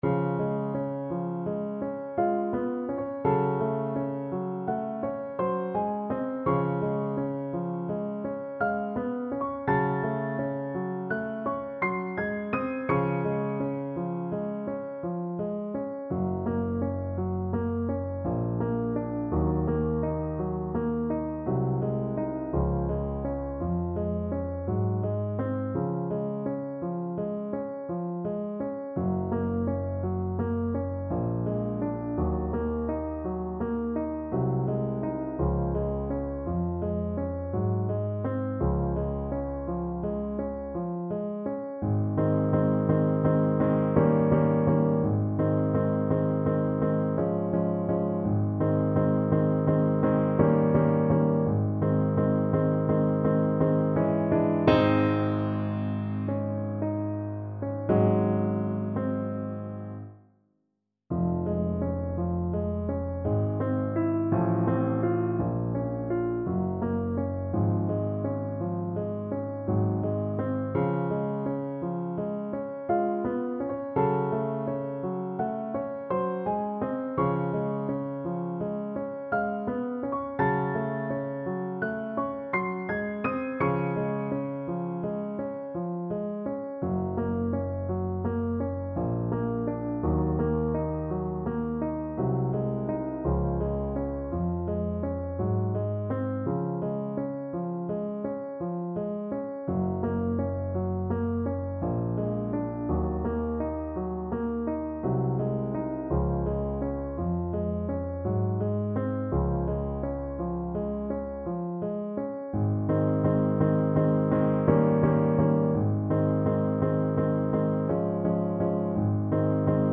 Play (or use space bar on your keyboard) Pause Music Playalong - Piano Accompaniment Playalong Band Accompaniment not yet available transpose reset tempo print settings full screen
Db major (Sounding Pitch) (View more Db major Music for Trombone )
~ = 56 Ziemlich langsam
Classical (View more Classical Trombone Music)